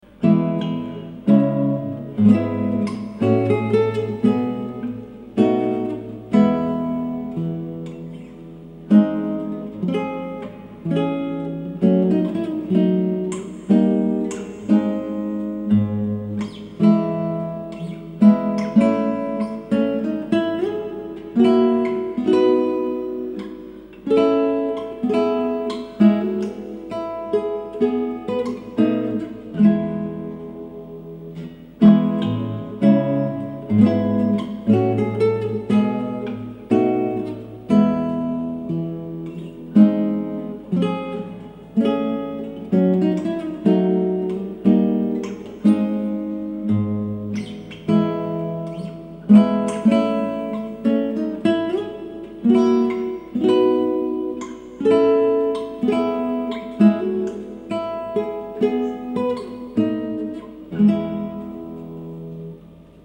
Prelude en E de Tarrega - Guitare Classique
Bravo pour le tien, il bien jouée :biggrin: